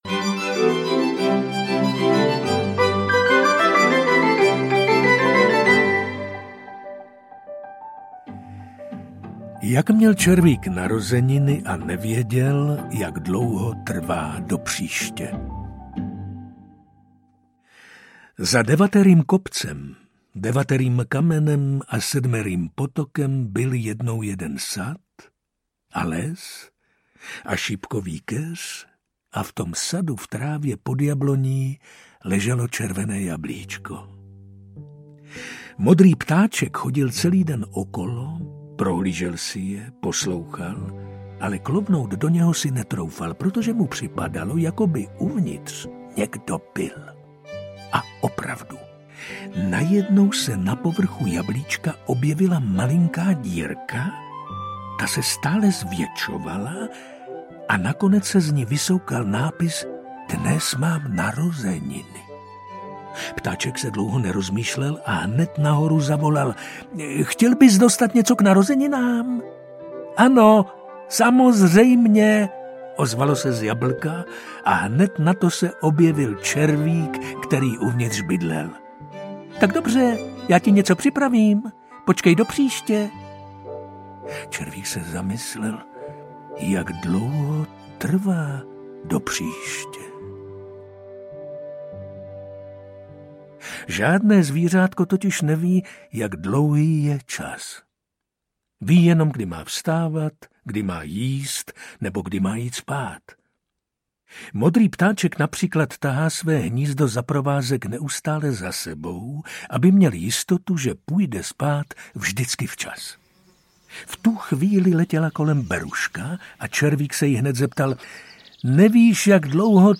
Starý sad audiokniha
Ukázka z knihy